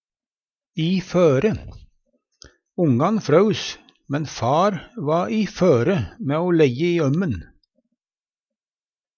DIALEKTORD PÅ NORMERT NORSK i føre i ferd med å, skal til å Eksempel på bruk Ongan fraus, men far va i føre mæ o leggje i ømmen.